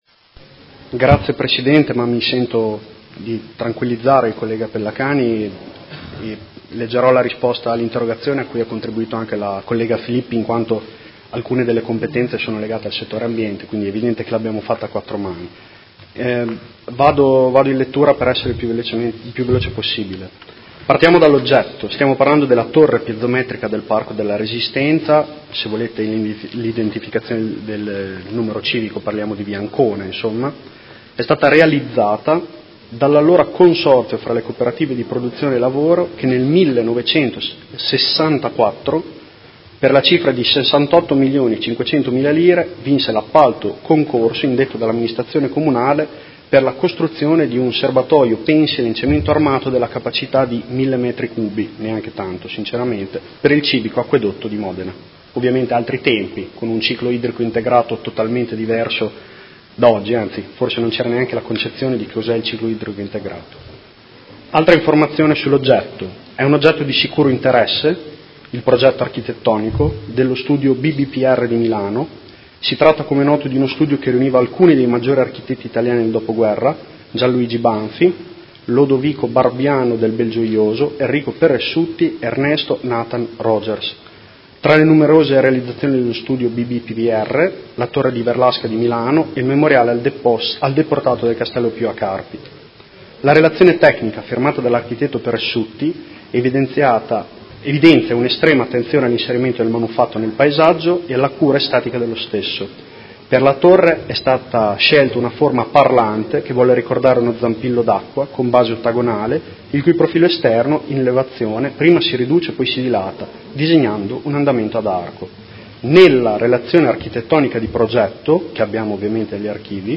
Seduta del 7/03/2019 Risponde. Interrogazione del Consigliere Pellacani (Energie per l’Italia) avente per oggetto: La torre dell’acquedotto del Parco della Resistenza è sicura?